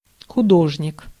Ääntäminen
IPA: /pɛ̃tʁ/